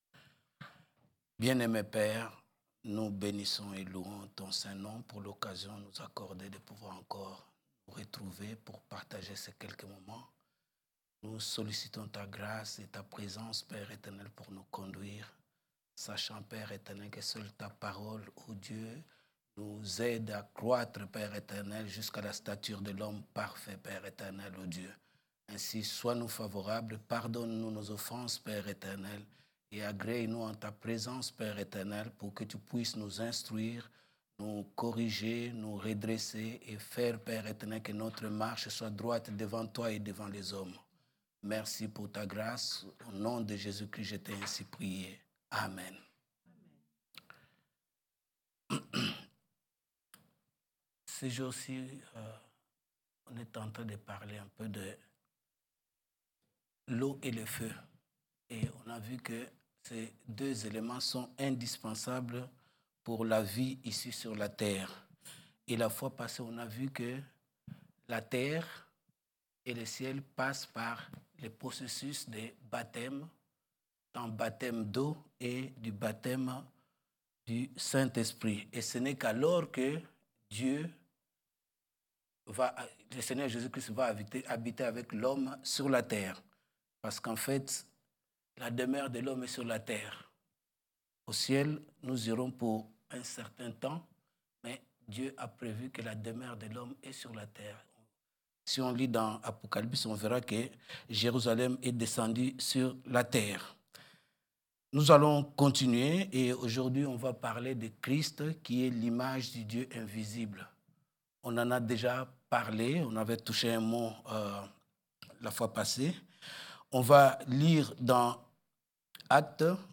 Catégorie: Prédications